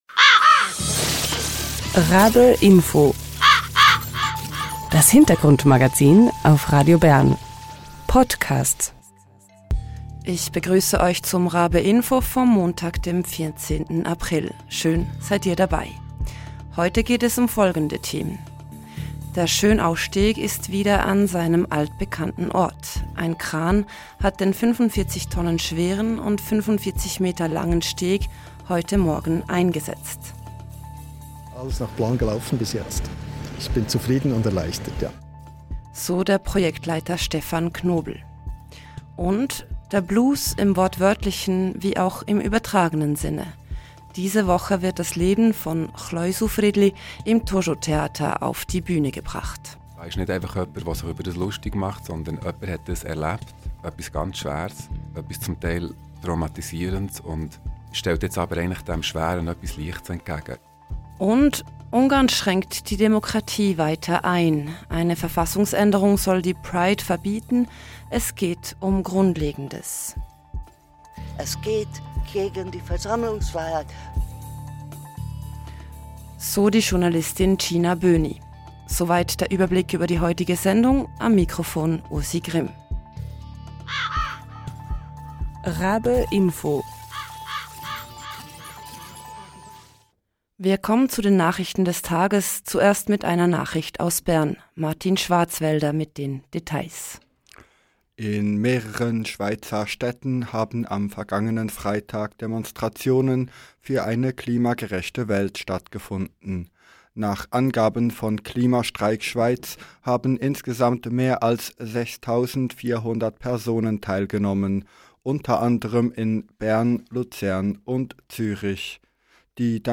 Beschreibung vor 1 Jahr Ein Kran hat den 45 Tonnen schweren und 45 Meter langen, frisch renovierten Schönausteg heute Morgen wieder an Ort und Stelle gesetzt. Wir waren vor Ort.